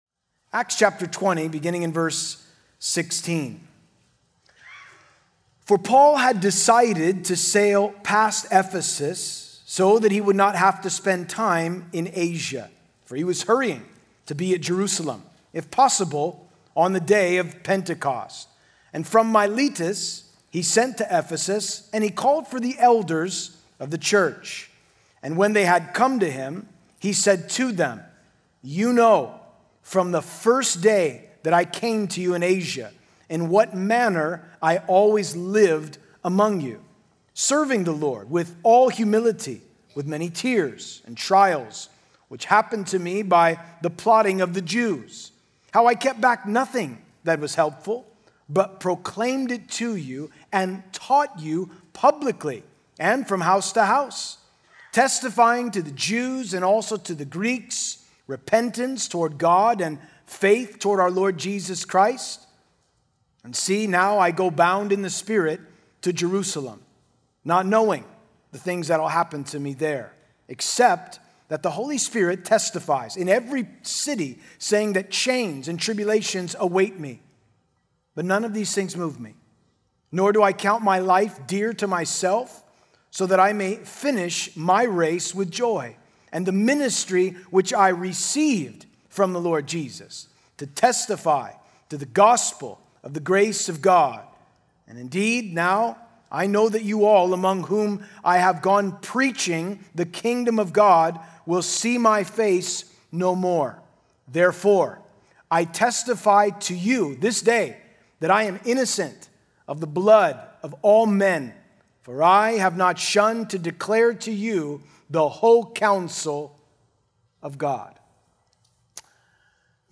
Sermon info
at the Nuts and Bolts of Expository Preaching Conference.